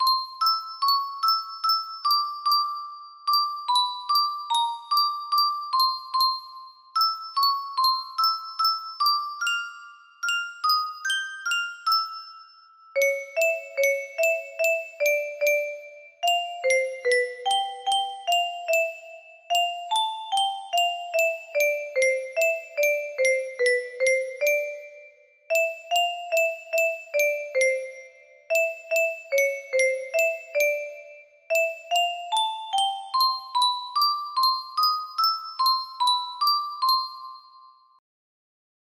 Cece music box melody
Grand Illusions 30 (F scale)